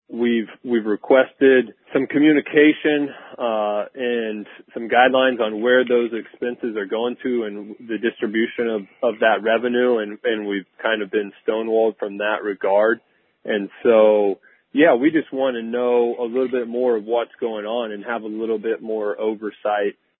Three Comments From Commissioner Jordan Brewer